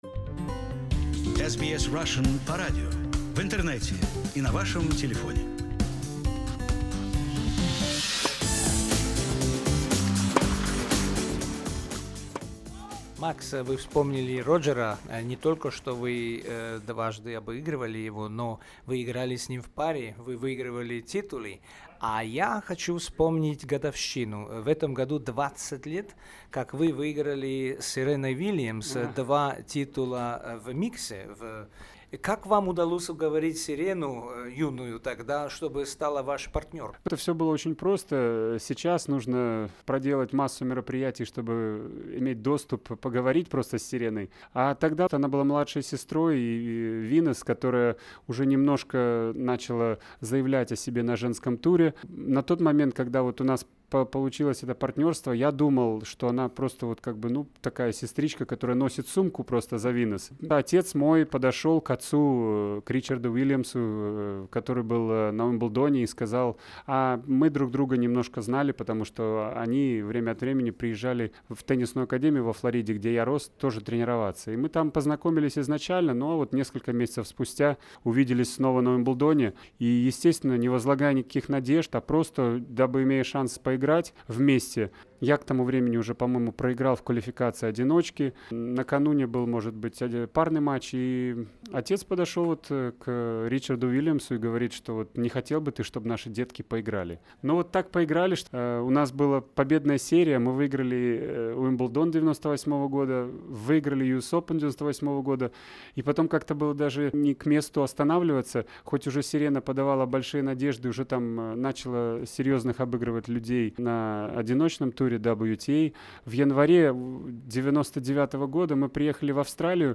In the second part of the extensive interview with Max Mirnyi whose contribution to development of tennis in Belarus is difficult to overestimate, he shares his memories the successful mixed doubles with the very young Serena Williams and his participation in London Olympic Games in 2012.